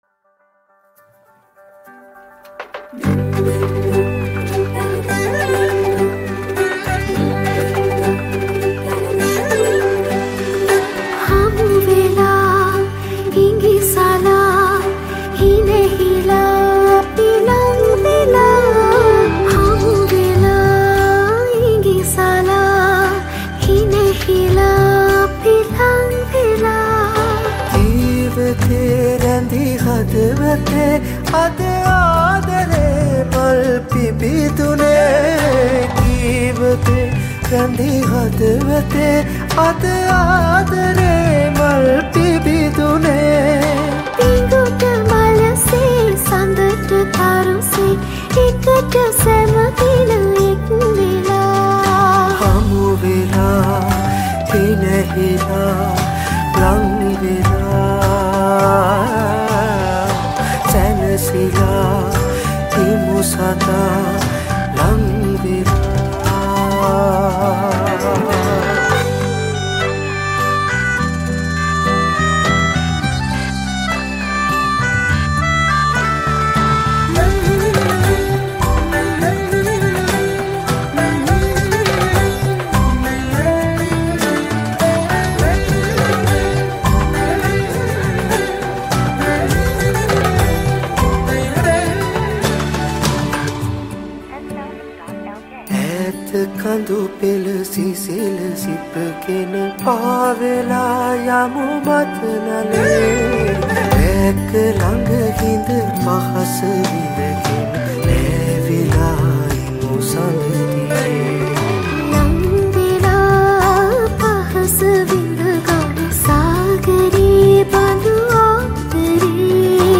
Flute
Bass
Veena
Guitars
Percussions
Chorus